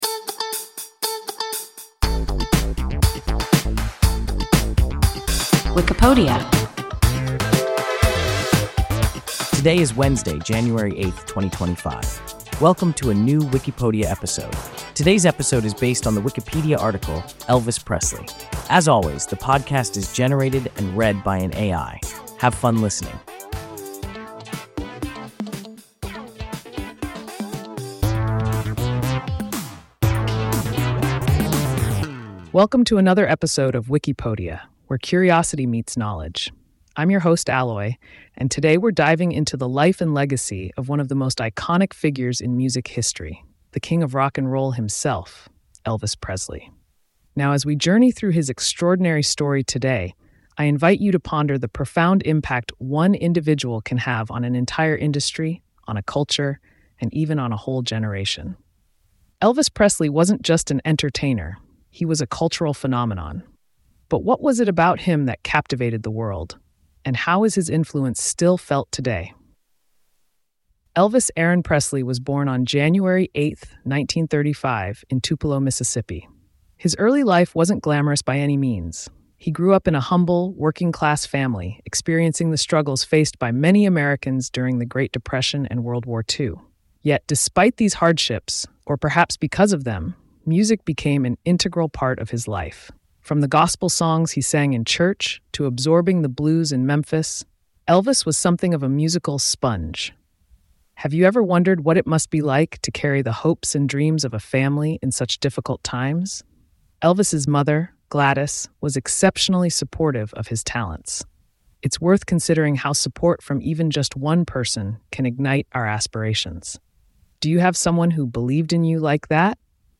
Elvis Presley – WIKIPODIA – ein KI Podcast